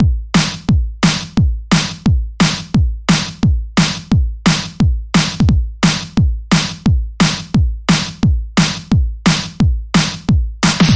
175 DNB POOP LOOP
标签： 175 bpm Drum And Bass Loops Drum Loops 2.77 MB wav Key : Unknown
声道立体声